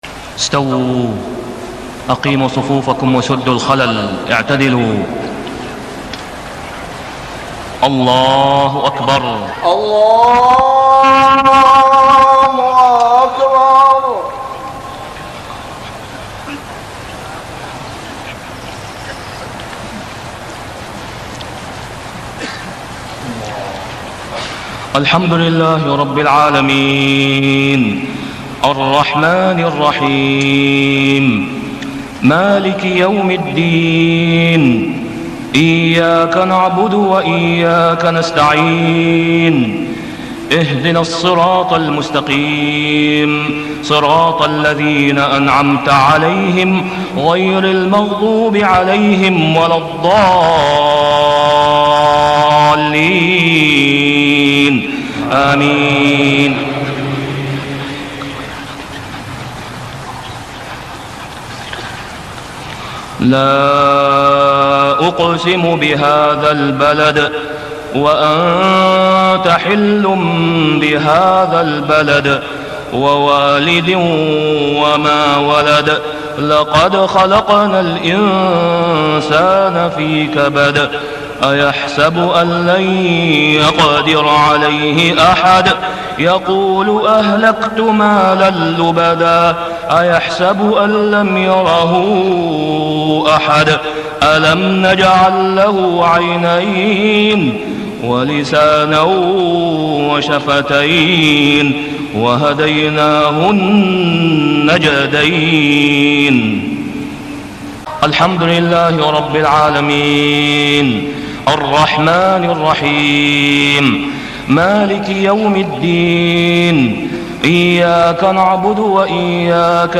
صلاة العشاء ذوالحجة 1421هـ سورة البلد > 1421 🕋 > الفروض - تلاوات الحرمين